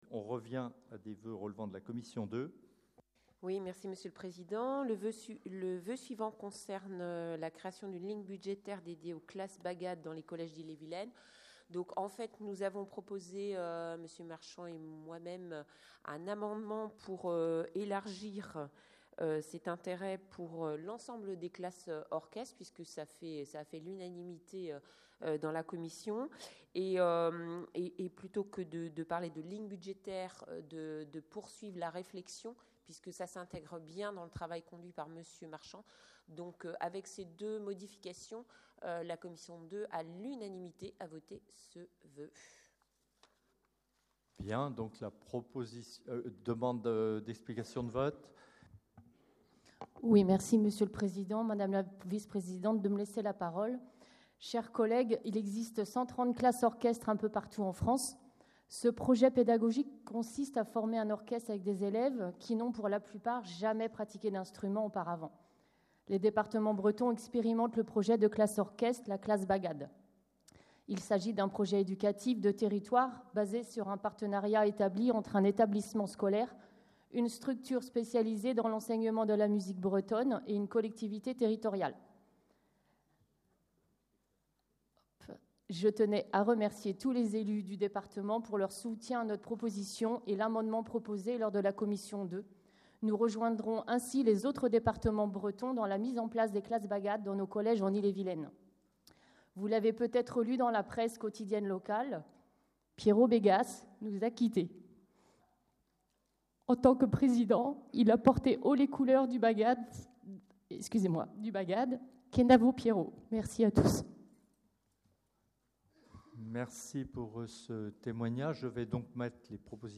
• Assemblée départementale du 29/06/23